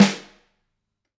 snare3.mp3